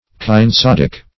Search Result for " kinesodic" : The Collaborative International Dictionary of English v.0.48: Kinesodic \Kin`e*sod"ic\, a. [Gr.